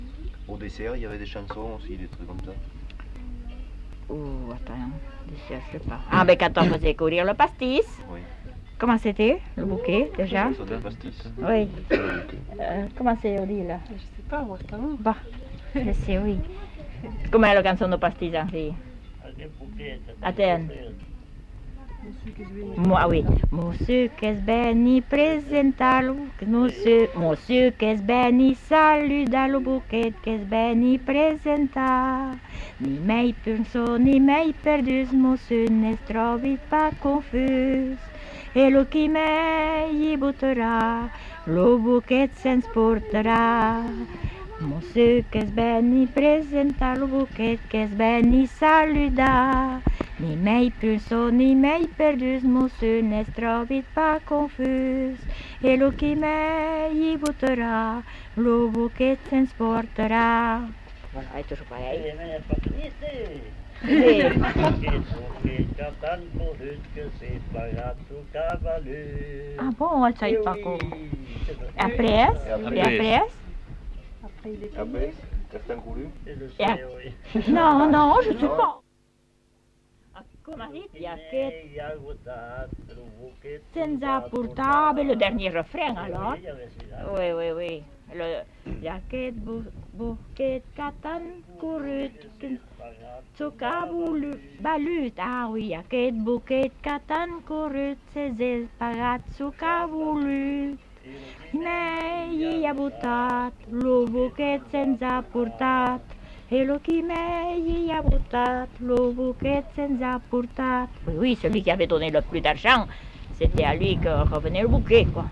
Aire culturelle : Petites-Landes
Lieu : Labrit
Genre : chant
Effectif : 2
Type de voix : voix de femme ; voix d'homme
Production du son : chanté